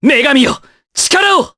Kasel-Vox_Skill3_jp.wav